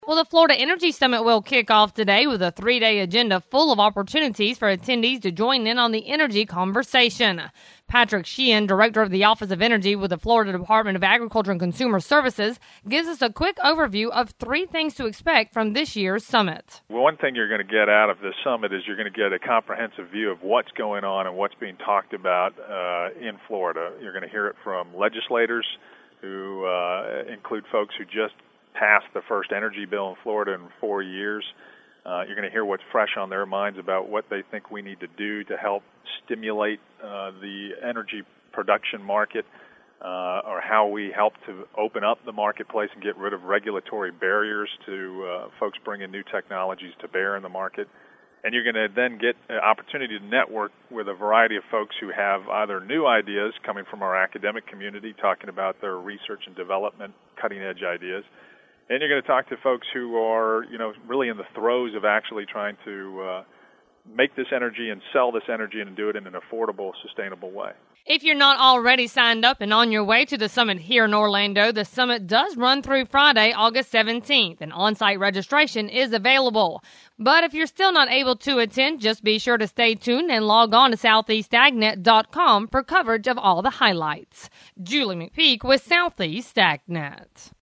The Florida Energy Summit will kick off today with a three day agenda full of opportunities for attendees to join in on the energy conversation. Patrick Sheehan, Director of the Office Energy with the Florida Department of Agriculture and Consumers Services, gives us a quick overview.